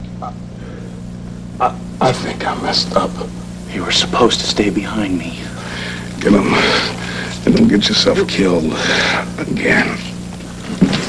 soundclips = GSM 6.10 - 22 KHz mono - should run on all Windows-systems!
I appologize for the bad quality of the sound and clips, they were captured with a camera right from the TV-screen, and besides that, the workprint-tape is the 1000th copy anyway =((( quite worn!